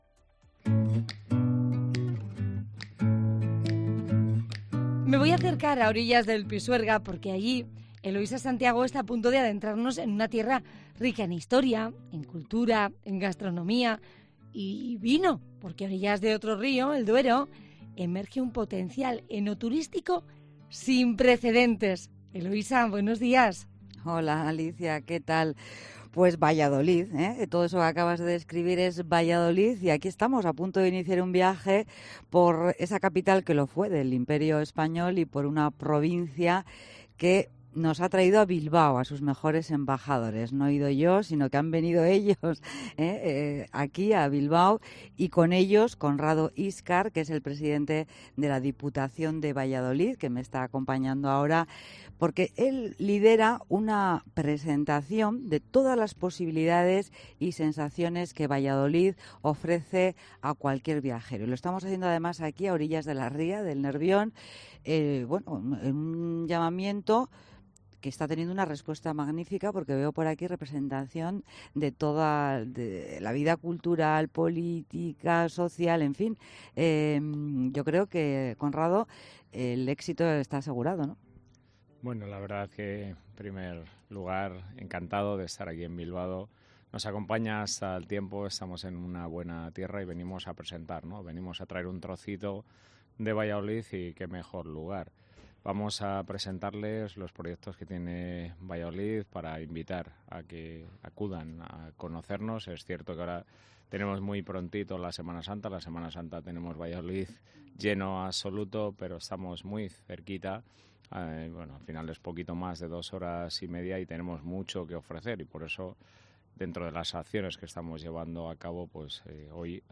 El presidente de la Diputación de Valladolid, Conrado Íscar, ha presentado en COPE Euskadi algunos de los principales productos turísticos que ofrece la provincia de Valladolid